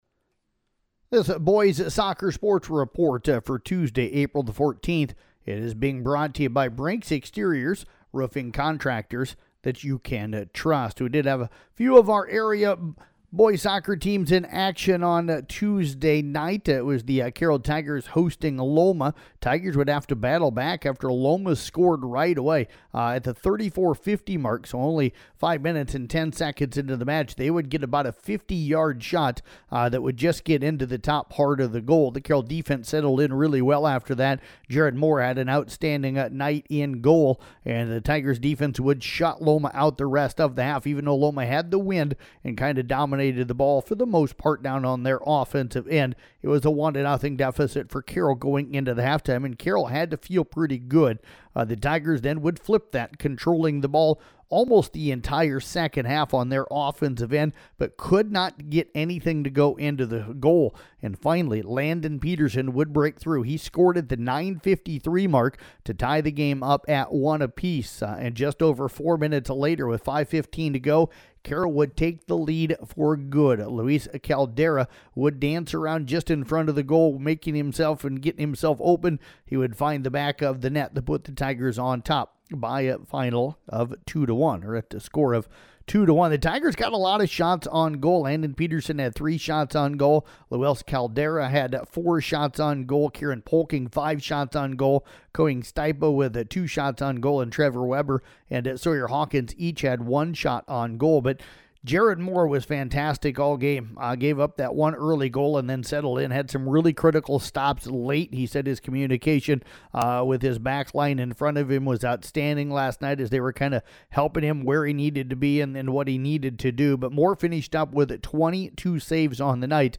Below is an audio recap of Boys Soccer for Tuesday, April 14th